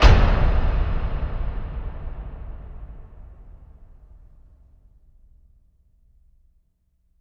LC IMP SLAM 4A.WAV